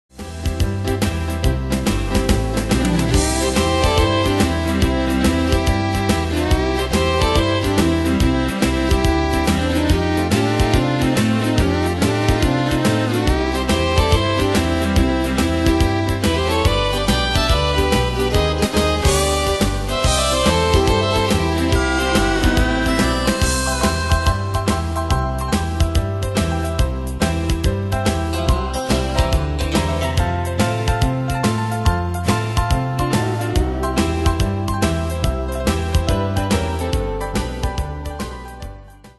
Style: Country Ane/Year: 1996 Tempo: 142 Durée/Time: 3.13
Danse/Dance: TripleSwing Cat Id.